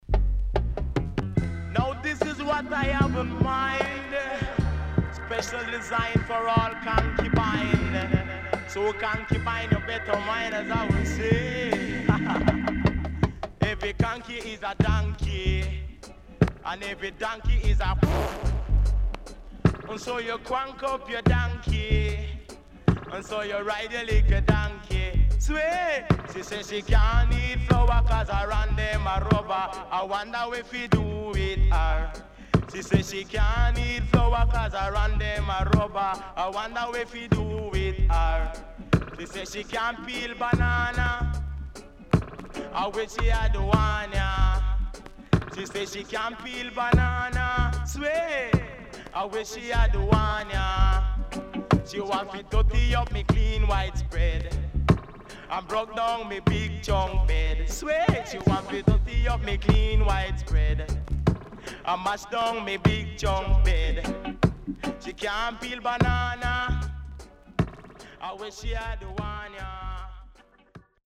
HOME > REGGAE / ROOTS  >  70’s DEEJAY
SIDE A:所々チリノイズがあり、少しプチノイズ入ります。